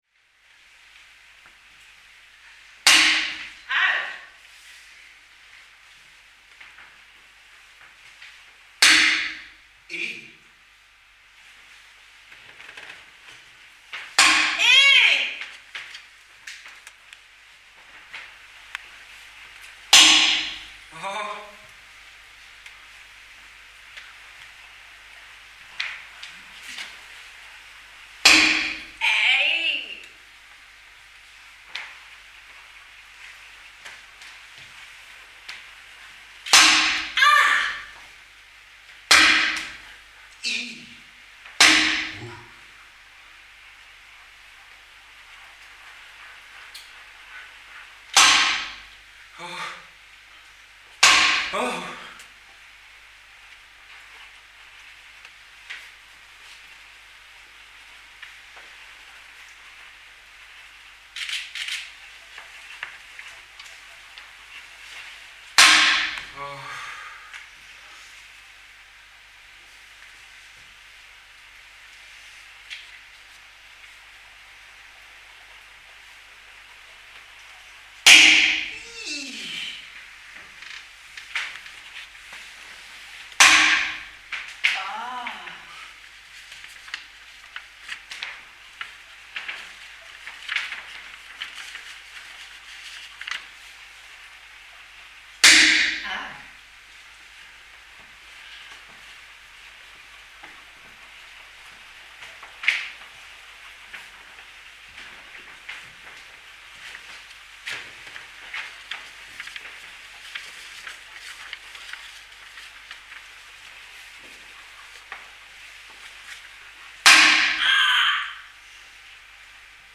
• Museum of performed scores, Studio Loos, 2011
It resulted in three playful a-capella pieces performed on the MoPS evening